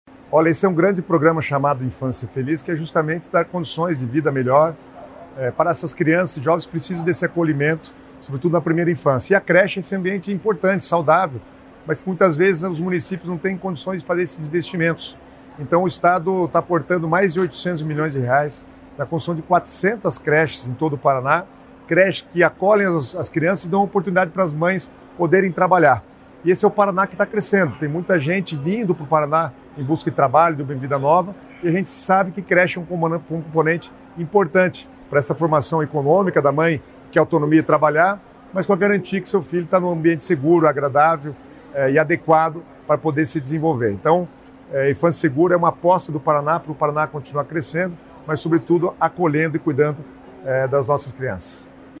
Sonora do secretário Estadual das Cidades, Guto Silva, sobre o avanço do programa Infância Feliz
sonora guto sobre infancia feliz.mp3.mp3